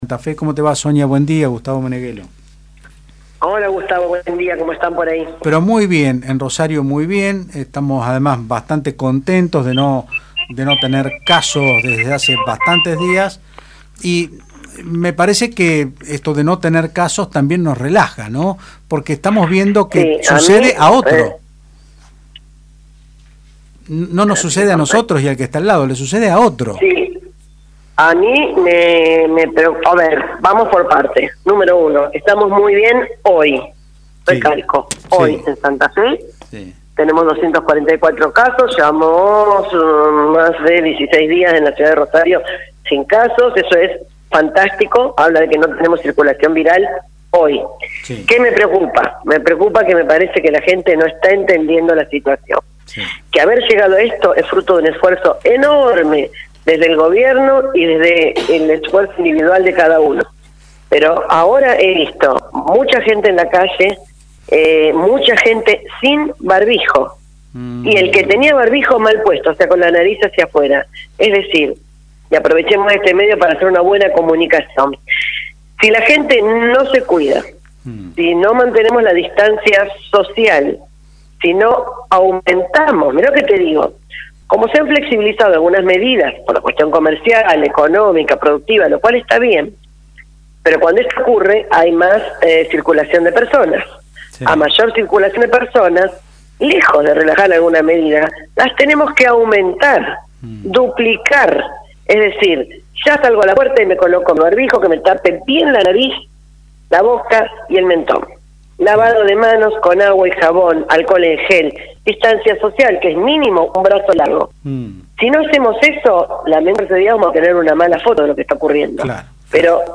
La secretaria de Salud Sonia Martorano dijo en Otros Ámbitos (Del Plata Rosario 93.5) que estar en fase 4 no quiere decir que tengamos que relajarnos pero la gente parece que no entiende la situación.